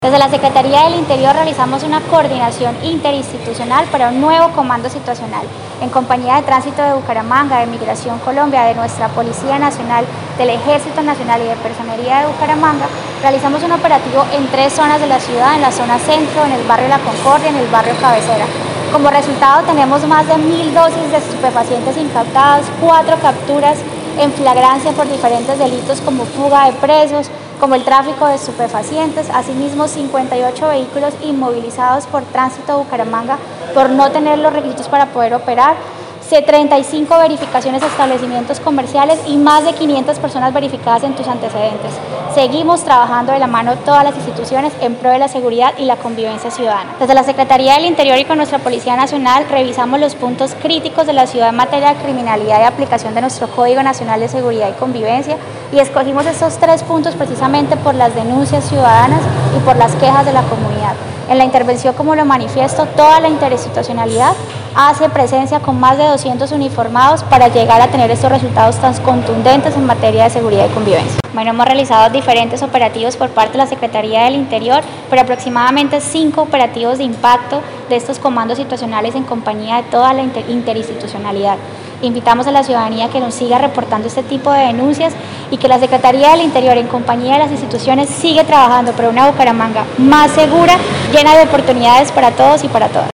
Melissa-Franco-subsecretaria-del-Interior-de-Bucaramanga-3.mp3